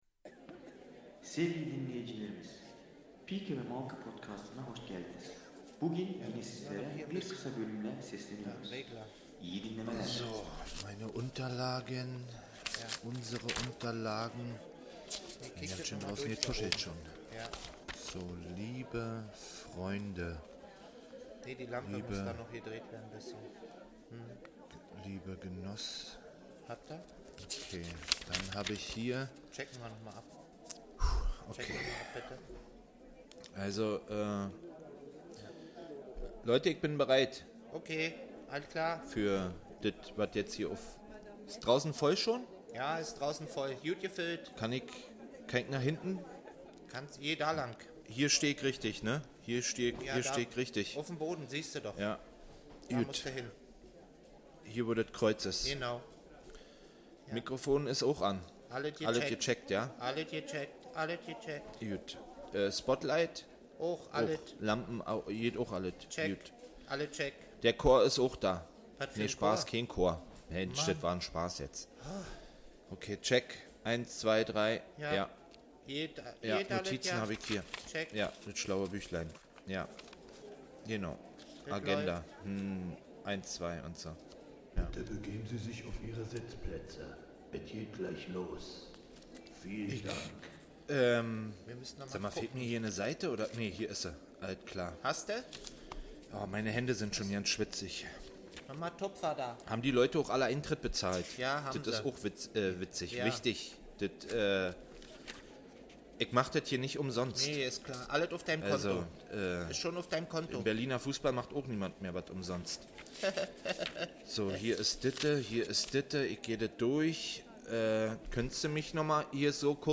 Möchtegern Live aus einem Berliner Theater und mit/ohne Publikum. Eine Folge bestehend aus Sprachnachrichten und Meinungen.